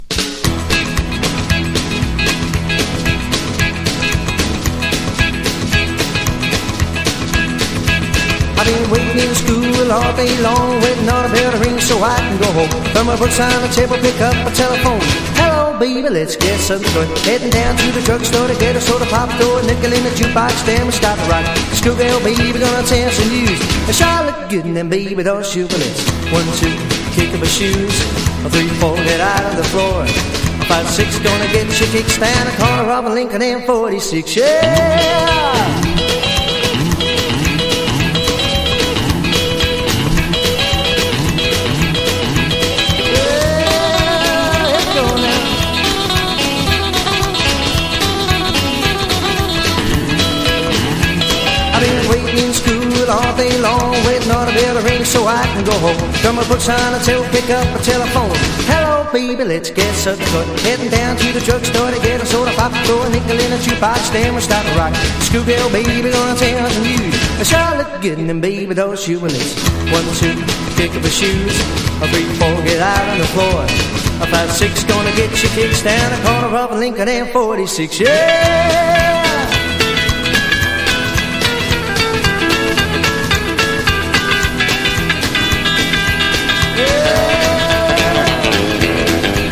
爽快感のある青春ロカビリー・ナンバー
ROCKABILLY / SWING / JIVE